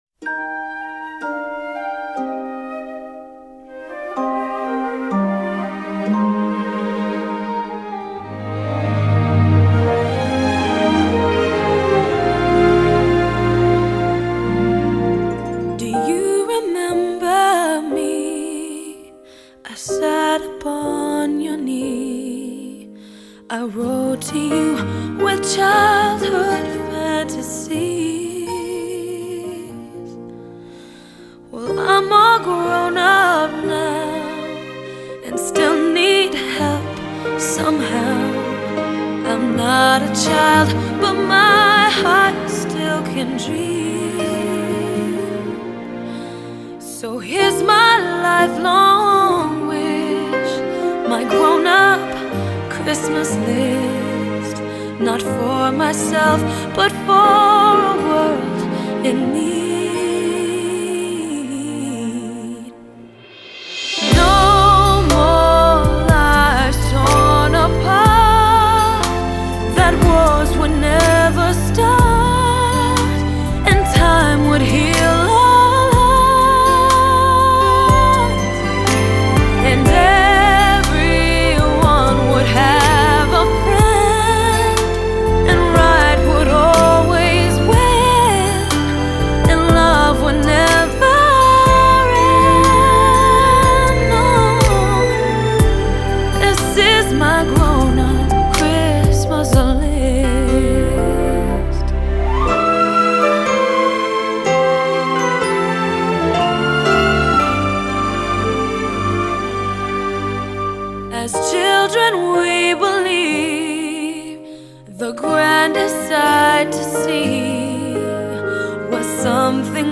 此張專輯一共彙集了13首最熱門的聖誕音樂。